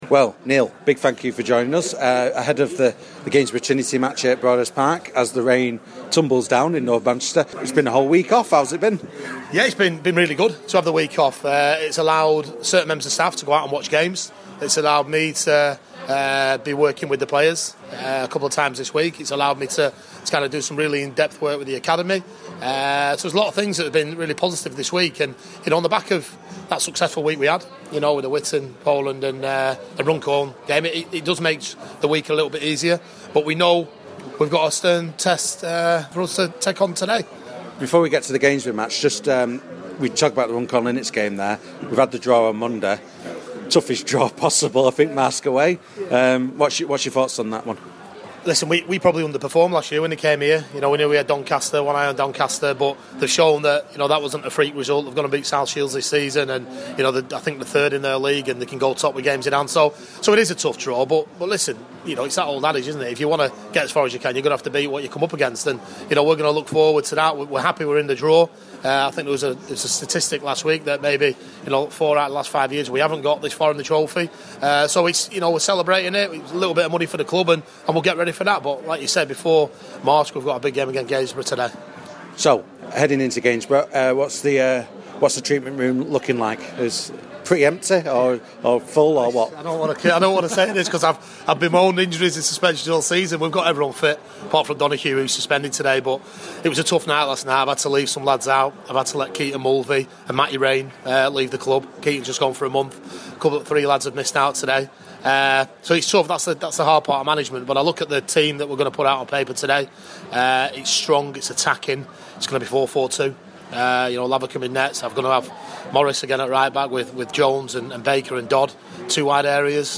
Pre Match Interview